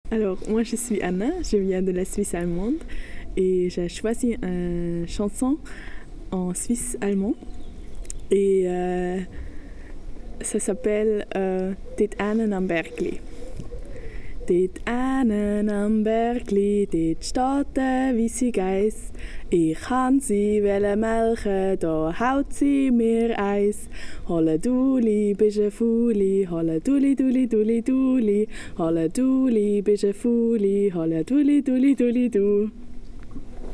comptine en suisse allemand